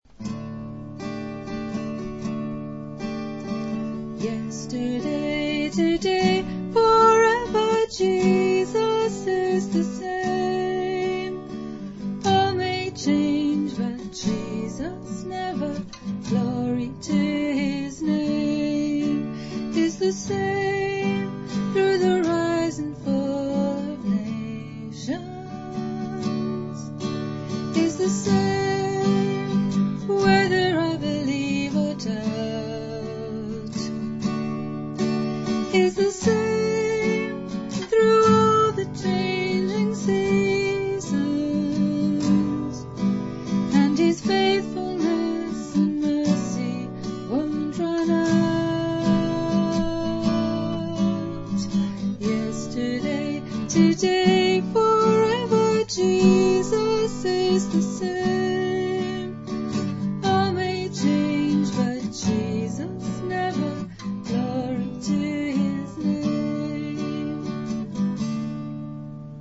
B. SONG (link to music in credits below)